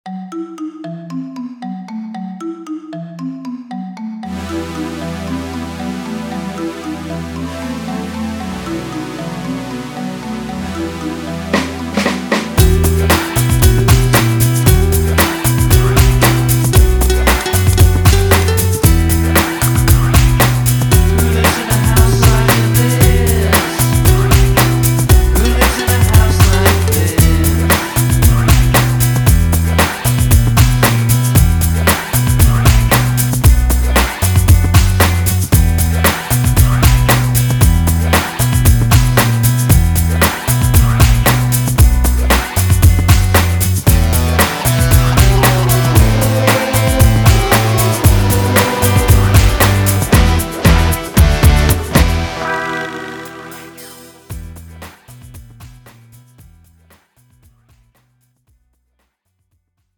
MR 반주입니다.